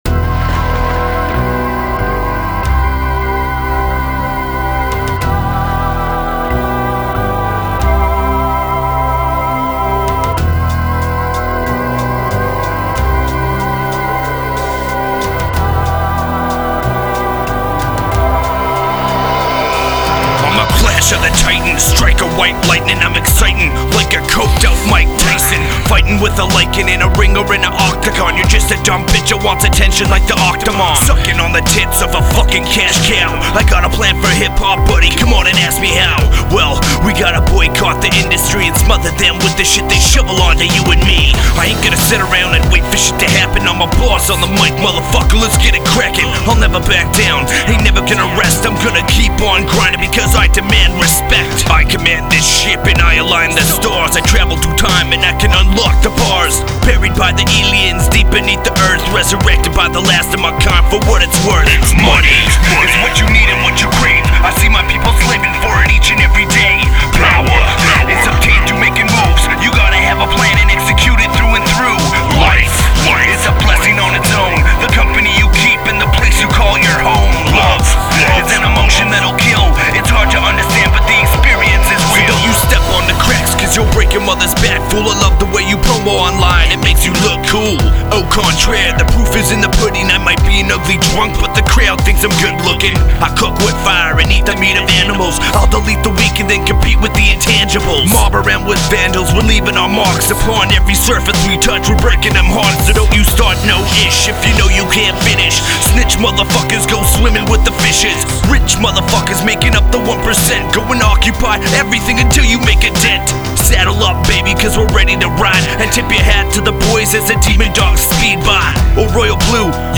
energetic tracks with dark undertones
real hip hop from the heart
Recorded at Ground Zero Studios